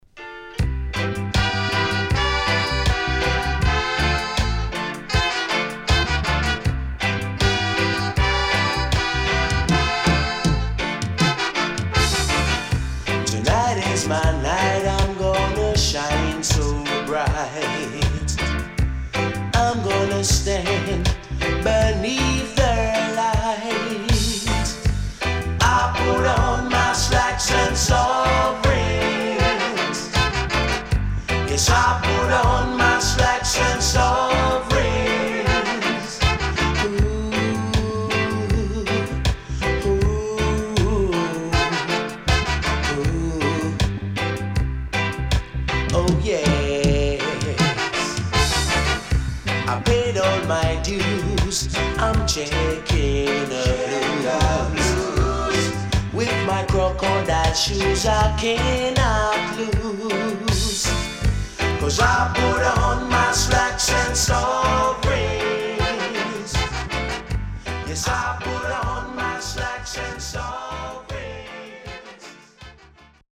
W-Side Great UK Lovers.Good Condition